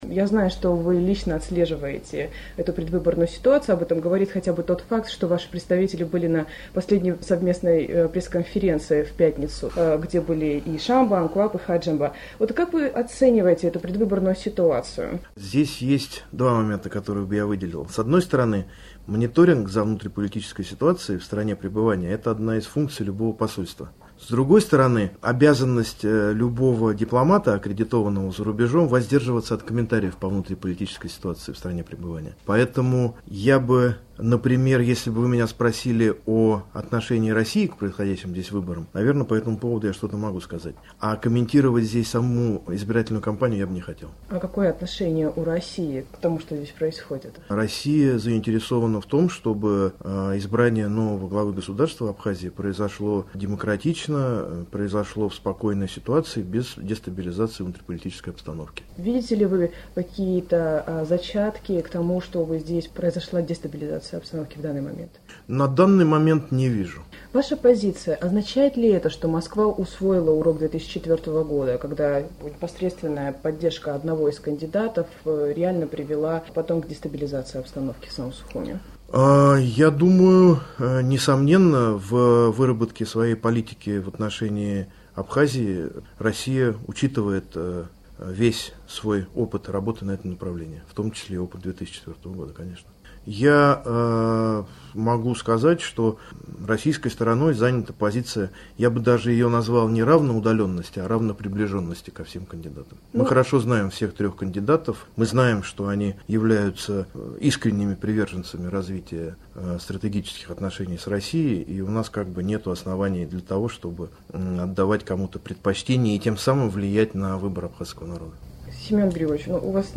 Интервью с Семеном Григорьевым